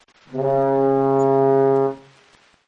Tesla Lock Sound Horn – Boat
Horn Boat sound
(This is a lofi preview version. The downloadable version will be in full quality)
JM_Tesla_Lock-Sound_Horn-Boat_Watermark.mp3